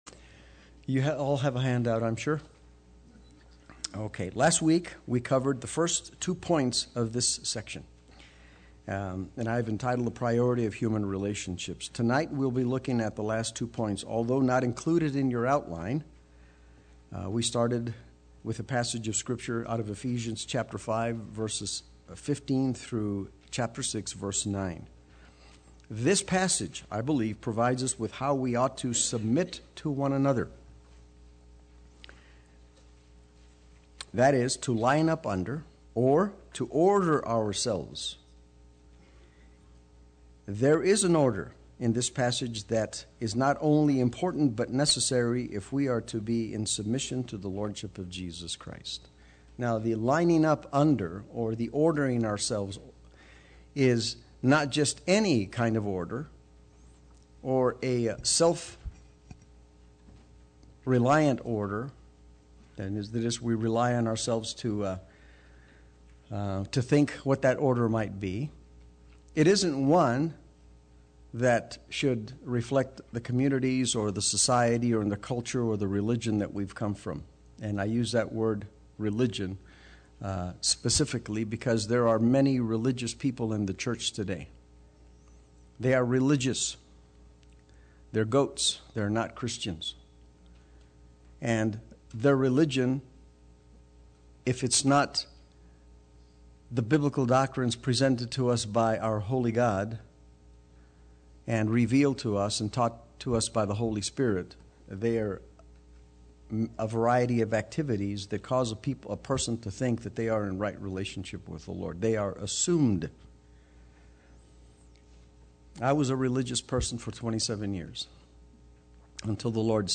Play Sermon Get HCF Teaching Automatically.
Lesson 5 Wednesday Worship